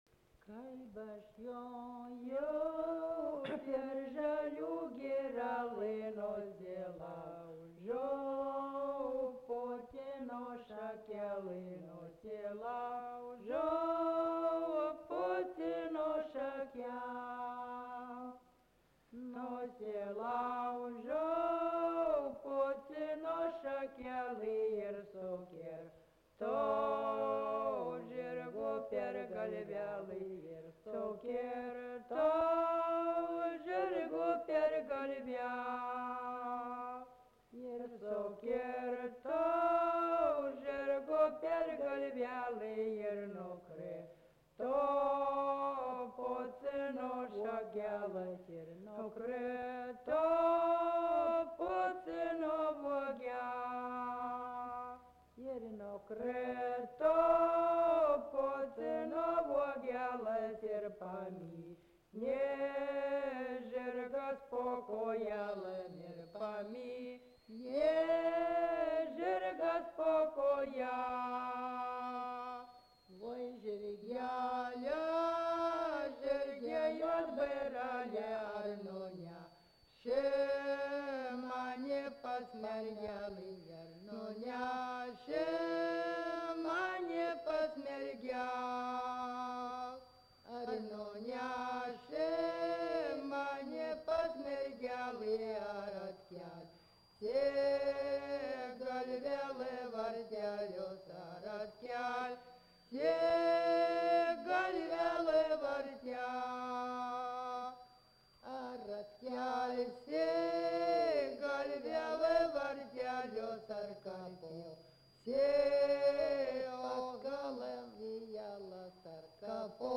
vokalinis
daina